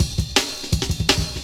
Rollin' Ride Cut 1.wav